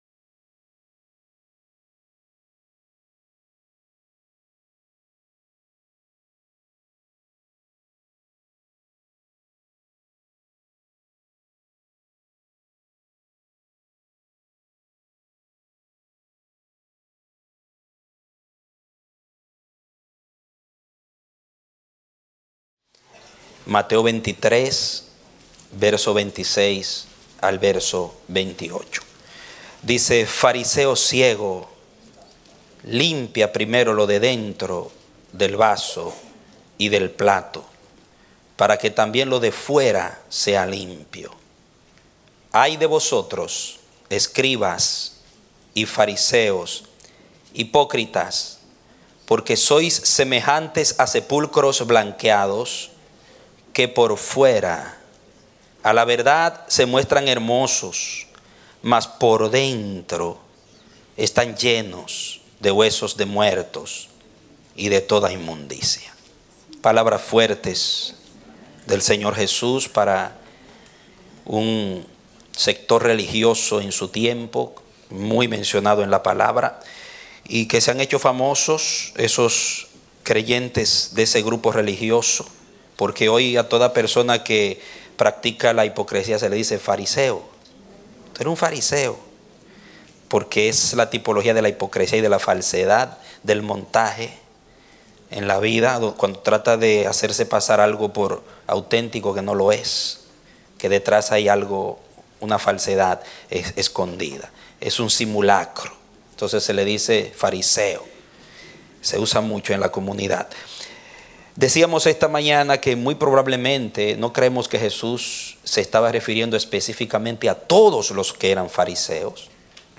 Predicado Domingo 1 de Mayo, 2016